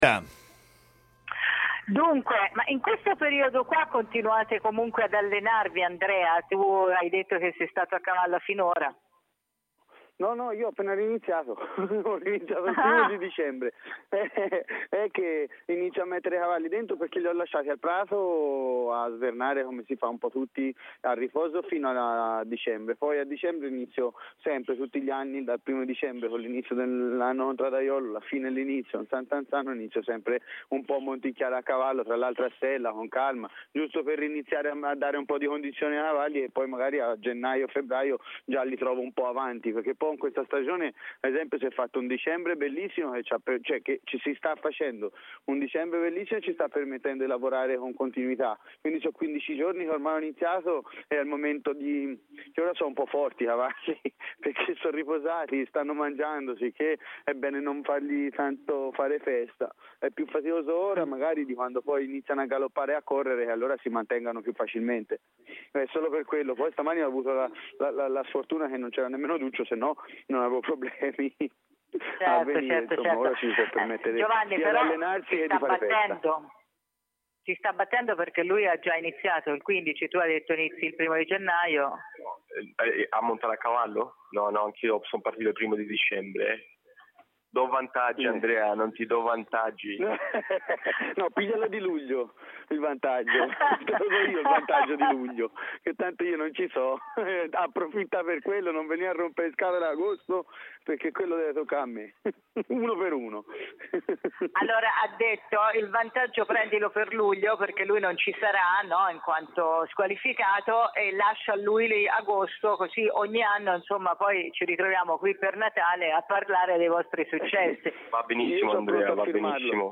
in diretta da New York Cafè